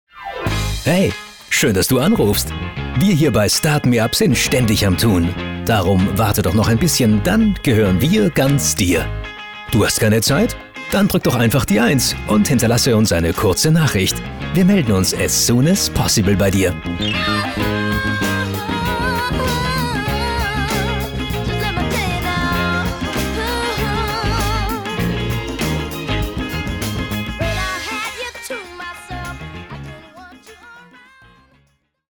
Voice Prompts